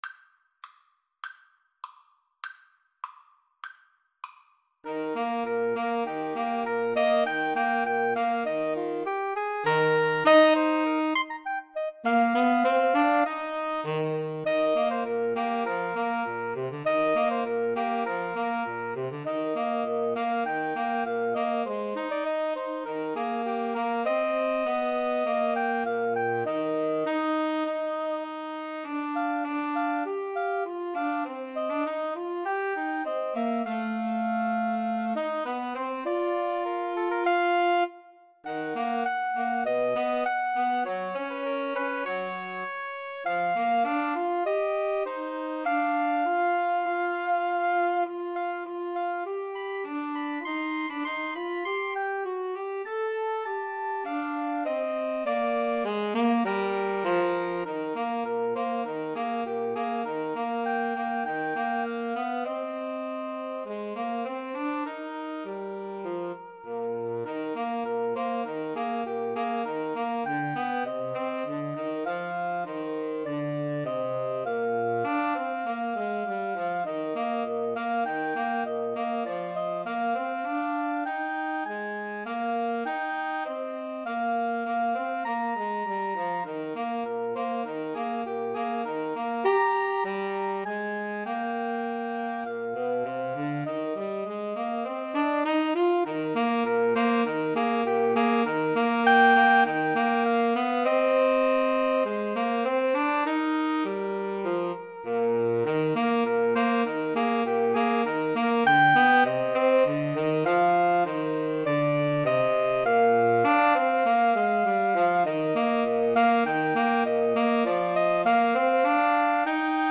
Soprano SaxophoneAlto SaxophoneTenor Saxophone
2/4 (View more 2/4 Music)
Tempo di Marcia
Pop (View more Pop Woodwind Trio Music)